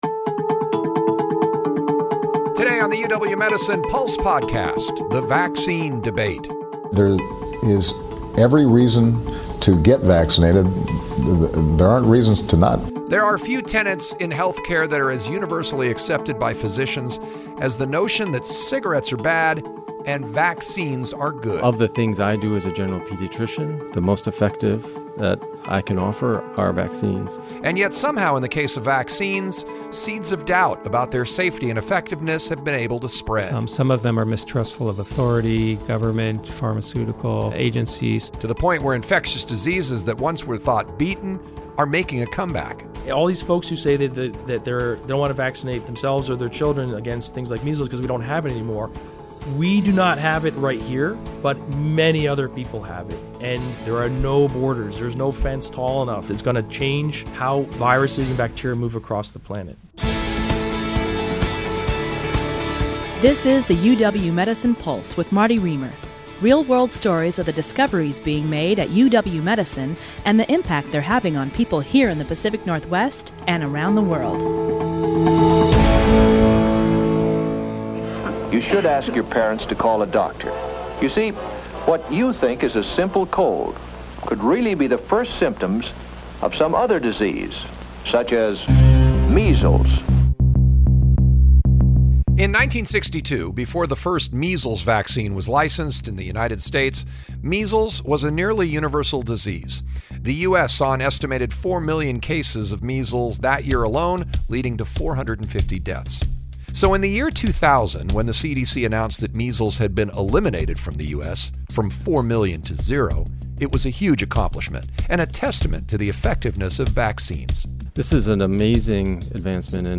Yet there is resistance from some parents about the safety and efficacy of vaccines. Today on the show we meet physicians and public health officials who aim to dispel the myths behind vaccinations and learn how UW Medicine is changing the conversation with their patients about the importance of immunizations.